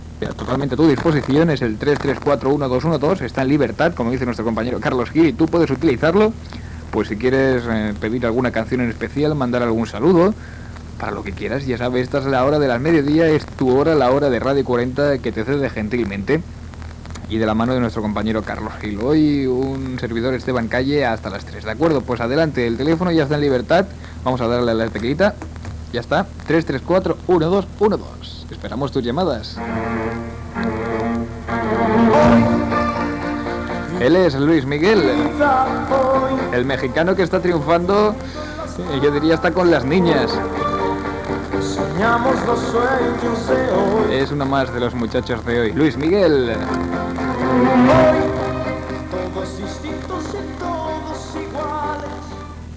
de02e92c42aa54922d98e420b785745f46d8aa3d.mp3 Títol Radio 40 Emissora Radio 40 Titularitat Tercer sector Tercer sector Comercial Descripció Telèfon de l'emissora i tema musical. Gènere radiofònic Musical